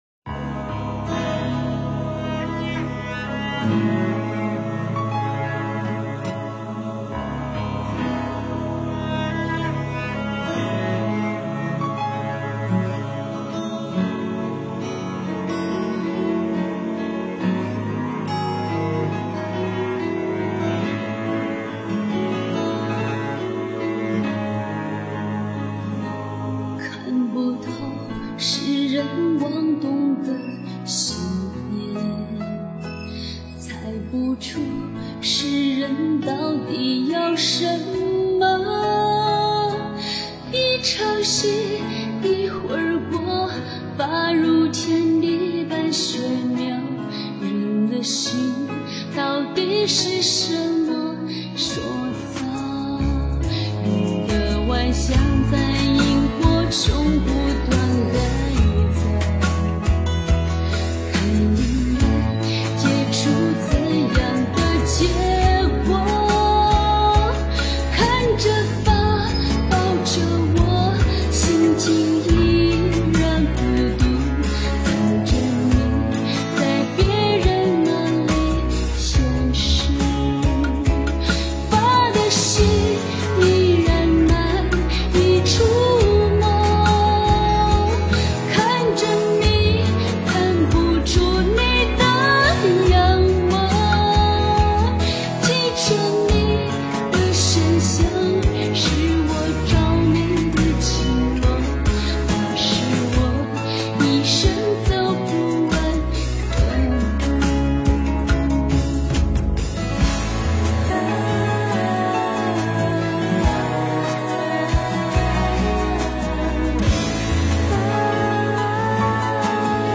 佛音 诵经 佛教音乐 返回列表 上一篇： 舍利花 下一篇： 俗人 相关文章 12.